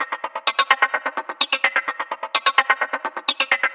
VEH1 Fx Loops 128 BPM
VEH1 FX Loop - 03.wav